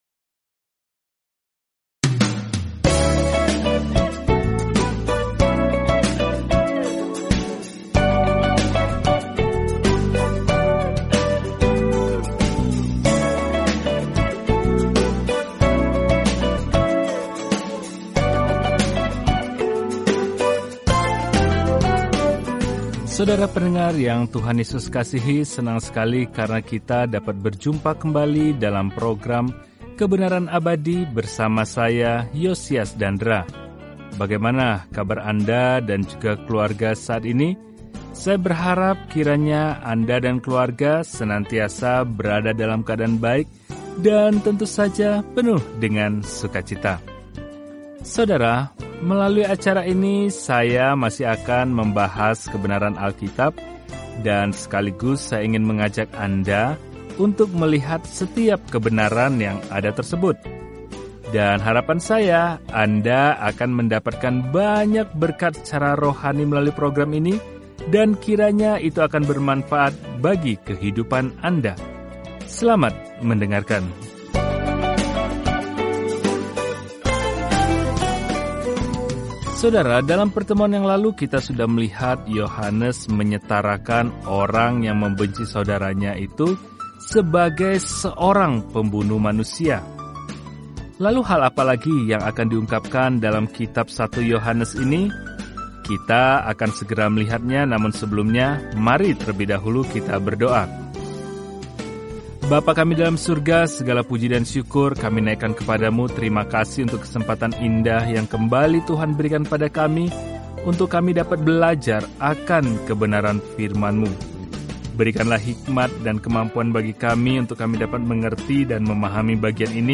Firman Tuhan, Alkitab 1 Yohanes 3:16-24 Hari 16 Mulai Rencana ini Hari 18 Tentang Rencana ini Tidak ada jalan tengah dalam surat pertama Yohanes ini – kita memilih terang atau gelap, kebenaran daripada kebohongan, cinta atau benci; kita menganut salah satunya, sama seperti kita percaya atau menyangkal Tuhan Yesus Kristus. Telusuri 1 Yohanes setiap hari sambil mendengarkan pelajaran audio dan membaca ayat-ayat tertentu dari firman Tuhan.